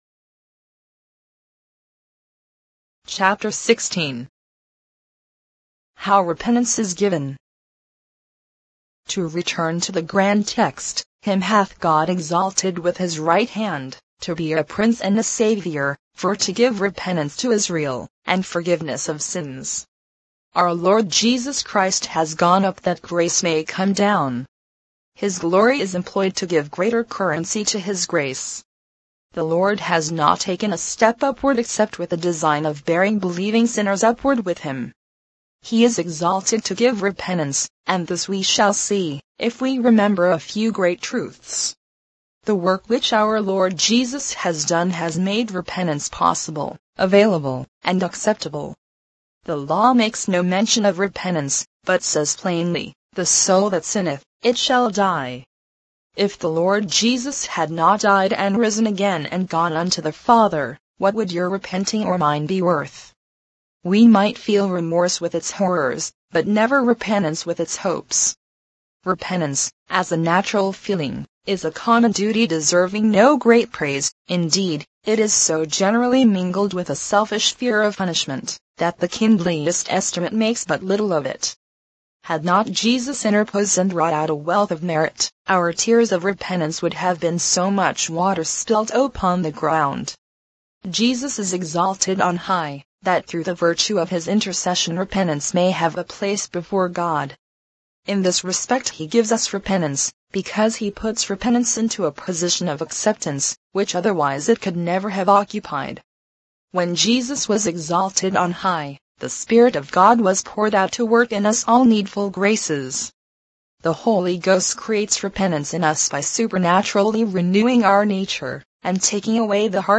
Free Christian Audio Books
Digital Narration for the 21st Century
All of Grace by Charles Spurgeon in mp3 audio -  32kbps mono